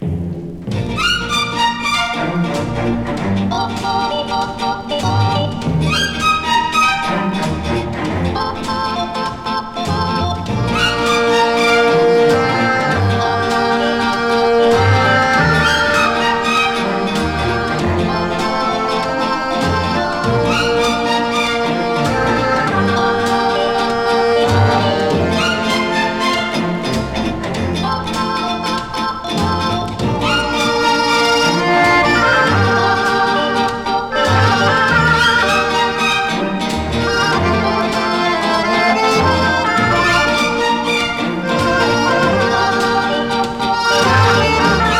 曲ごとの楽器選択が楽しく、演出も有り色取り取りのサウンドで新鮮さを感じます。
Jazz, Easy Listening, Mood　USA　12inchレコード　33rpm　Mono